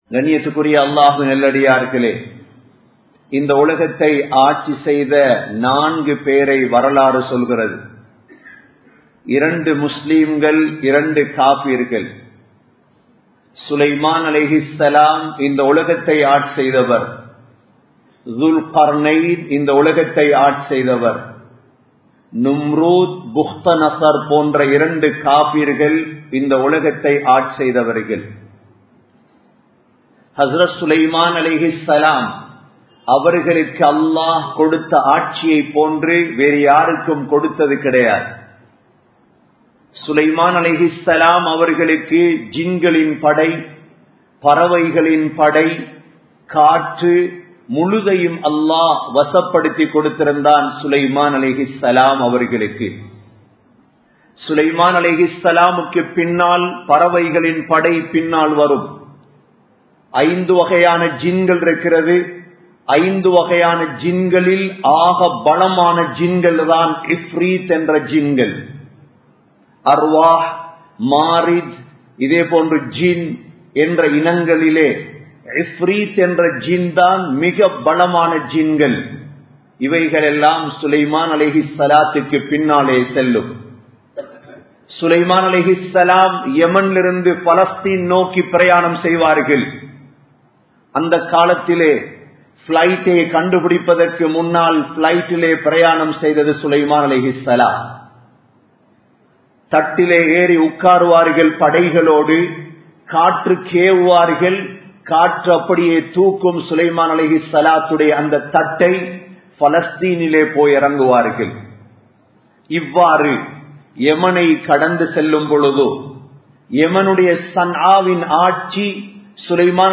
Muslimkale! Seeraaha Sinthiungal (முஸ்லிம்களே! சீராக சிந்தியுங்கள்) | Audio Bayans | All Ceylon Muslim Youth Community | Addalaichenai
Samman Kottu Jumua Masjith (Red Masjith)